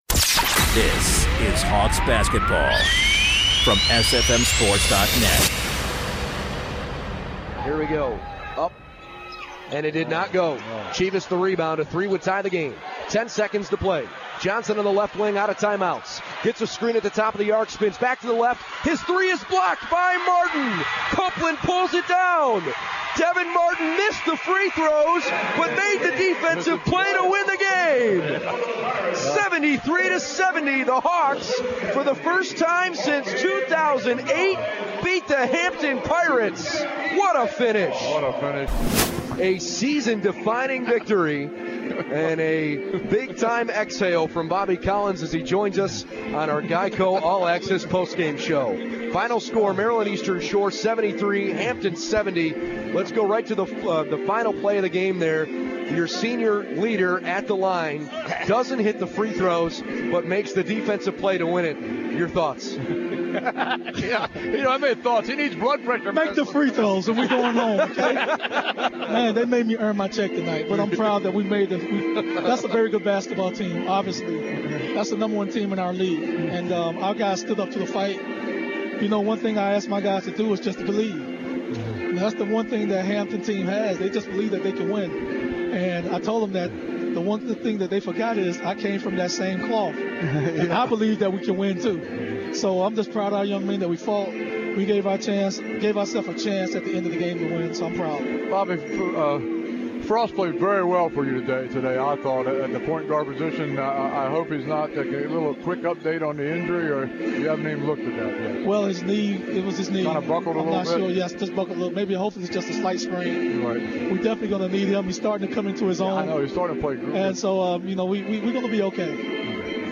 2/6/16: UMES Men's Basketball Post Game Show